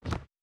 04_书店内_扶正书本.wav